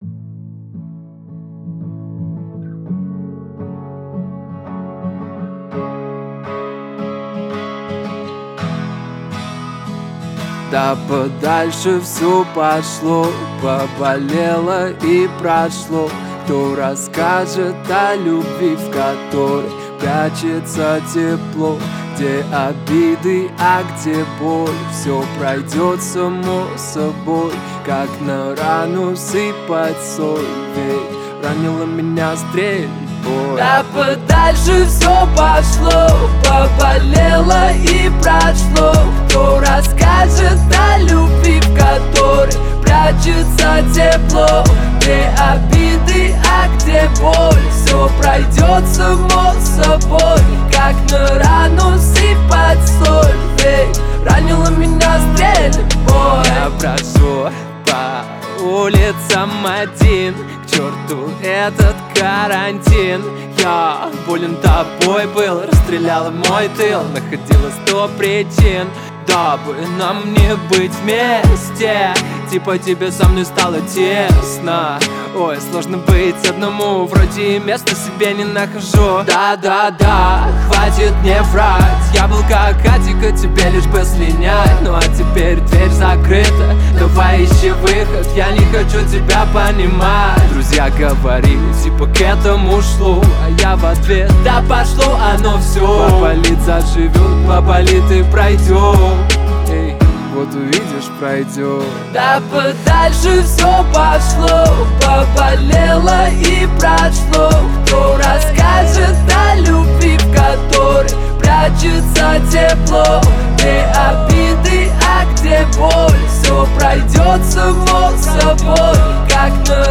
• Качество: 320 kbps, Stereo
российский рэп-исполнитель.